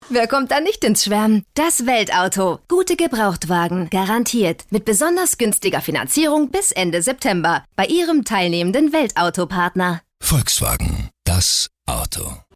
Ich bin Profi-Sprecherin und spreche für Sie Werbung, Audio Ads, Dokumentation, E-Learning, Zeichentrick, Jingles, Spiele.
Sprechprobe: Werbung (Muttersprache):